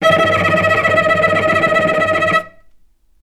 vc_trm-E5-mf.aif